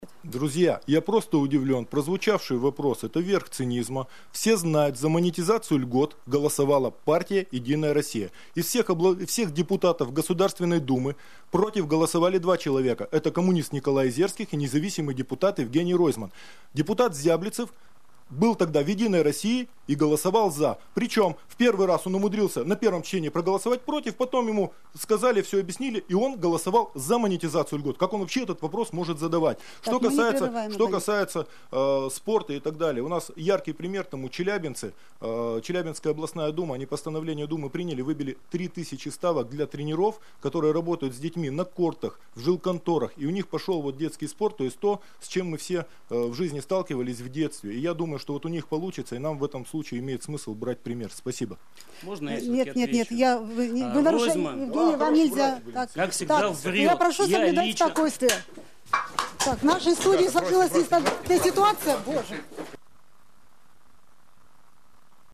Заявление одного из участников «драки кандидатов» в прямом эфире. ВИДЕО